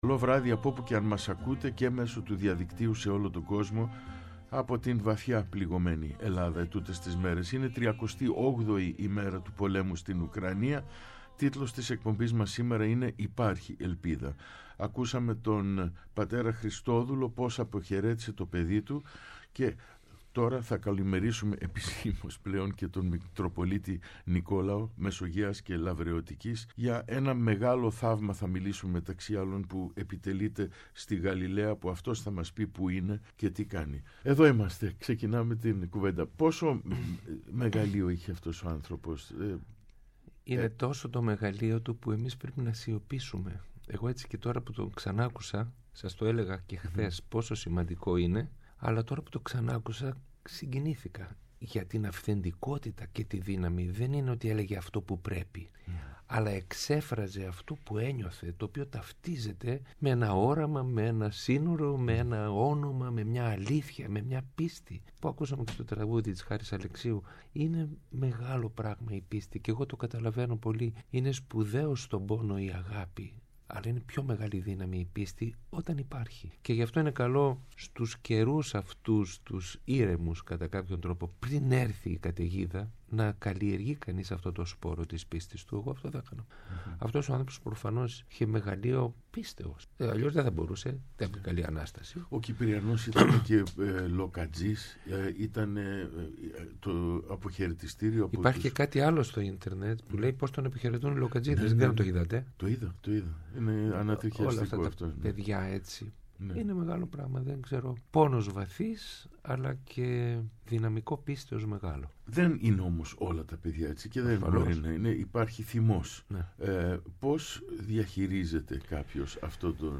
Ο Μητροπολίτης Μεσογαίας-Λαυρεωτικής Νικόλαος στο Πρώτο Πρόγραμμα | 10.03.23